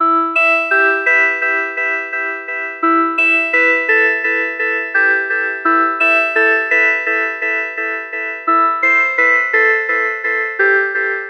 描述：循环，休息室
标签： 85 bpm Chill Out Loops Synth Loops 972.84 KB wav Key : C
声道立体声